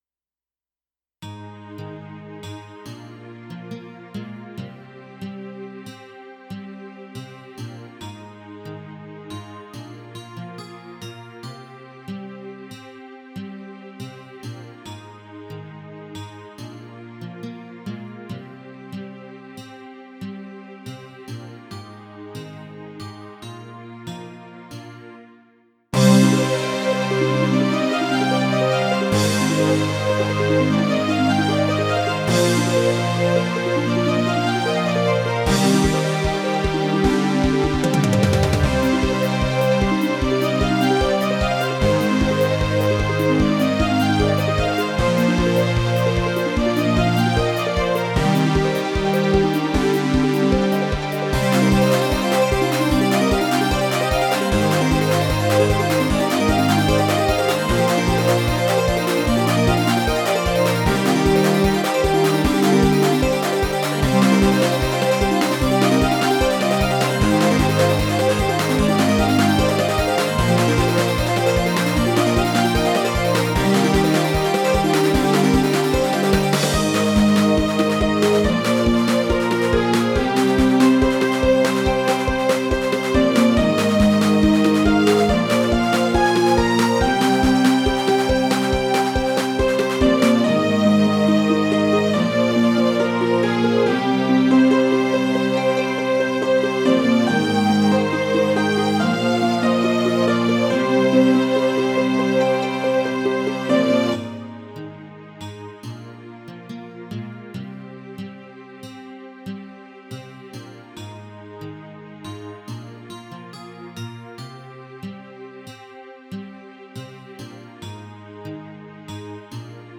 由Roland Sound Canvas 88Pro实机录制；MP3采样率44.1KHz，码率192Kbps。